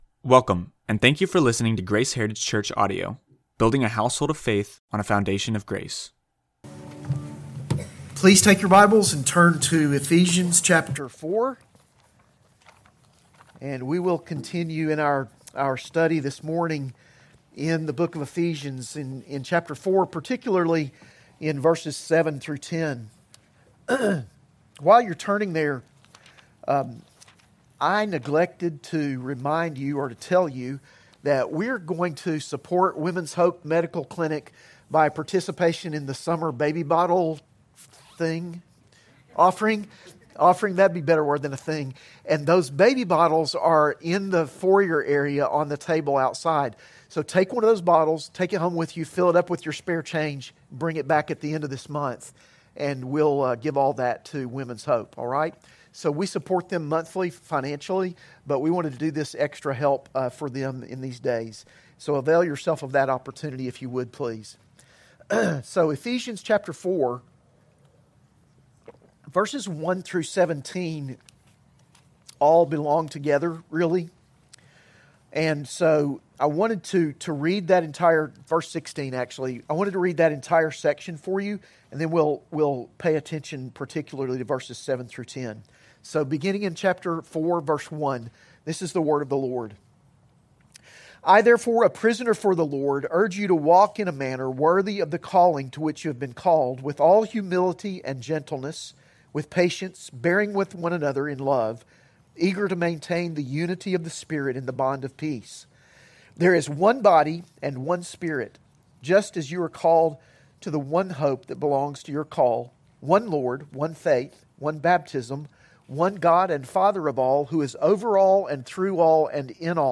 AM Worship Sermon